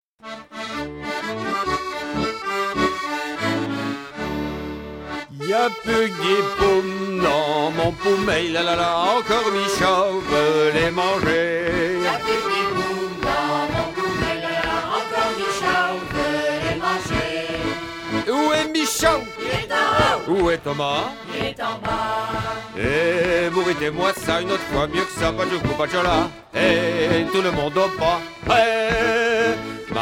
Marais Breton Vendéen
danse : ronde : grand'danse
Pièce musicale éditée